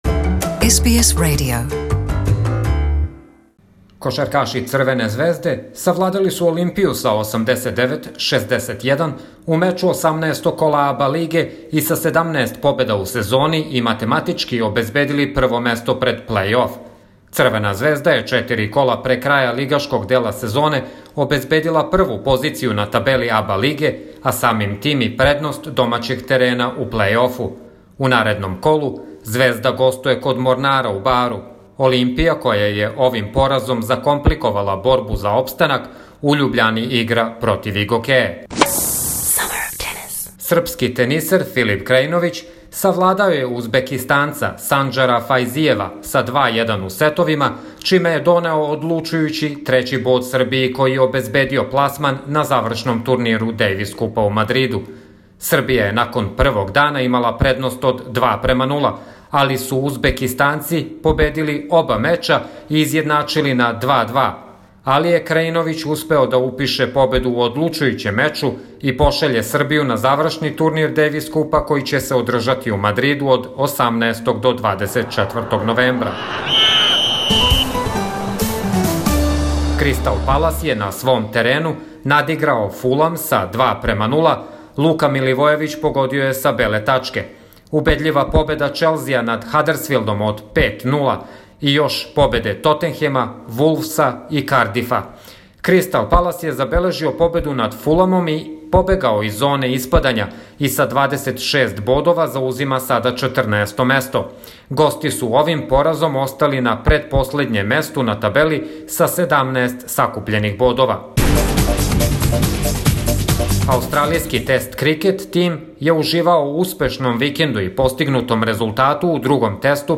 Спортски извештај